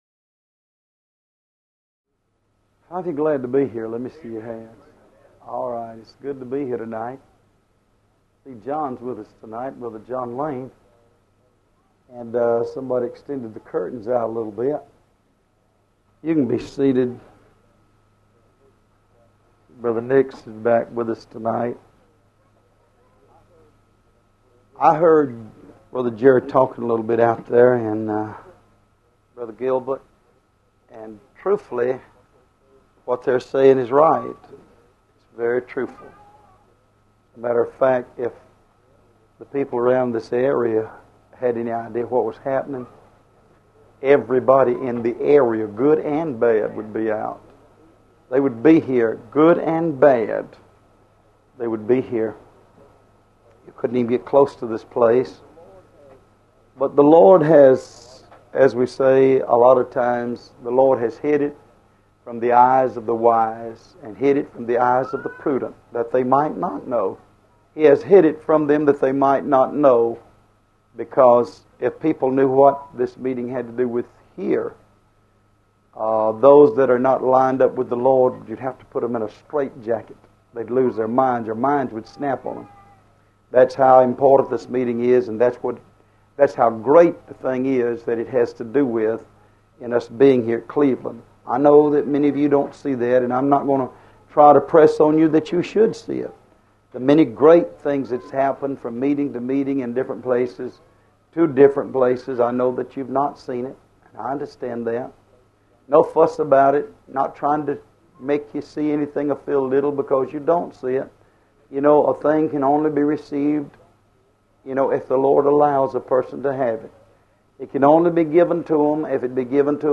Sermons Starting With ‘D’